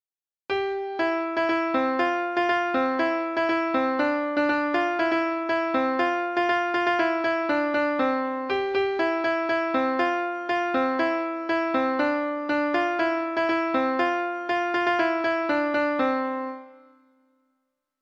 Treble Clef Instrument version
Folk Songs